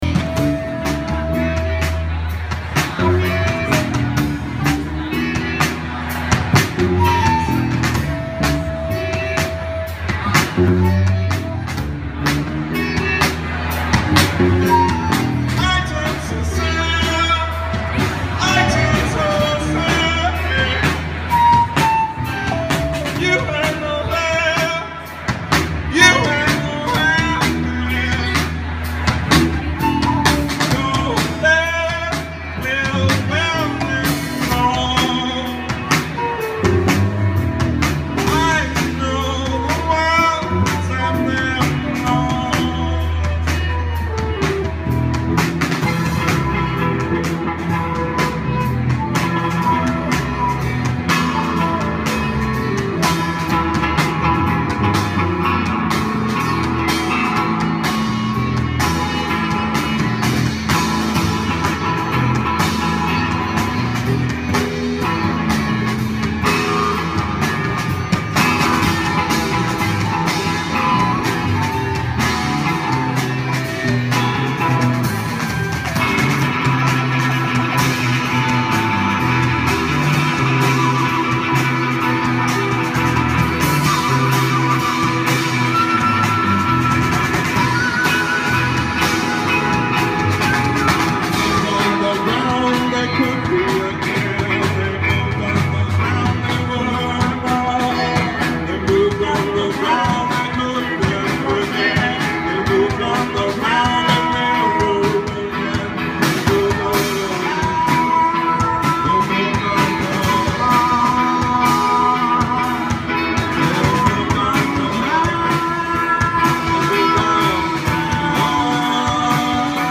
ALL MUSIC IS IMPROVISED ON SITE
bass/voice
flute/voice
drums
sitar